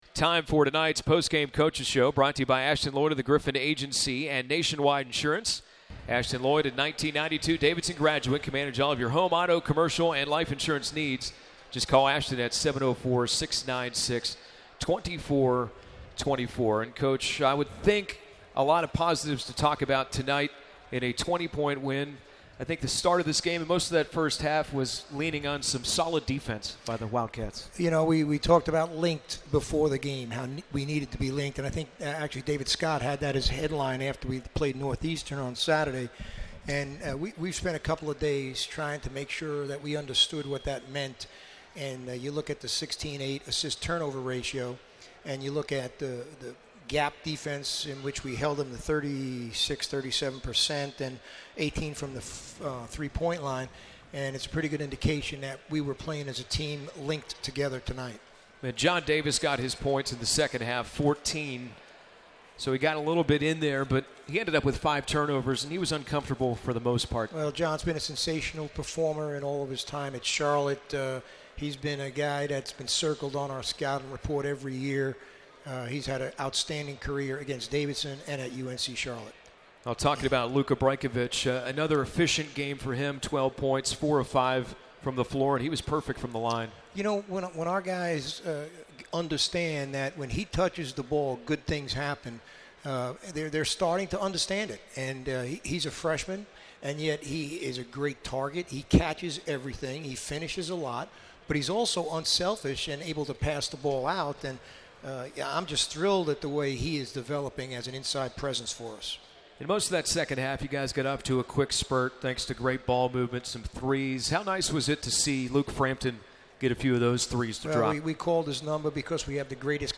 McKillop Postgame Interview